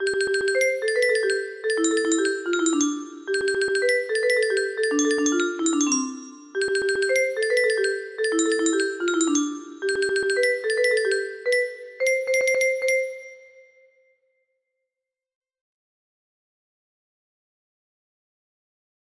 Ramón Borobia Cetina - Jota de los toros en Zaragoza music box melody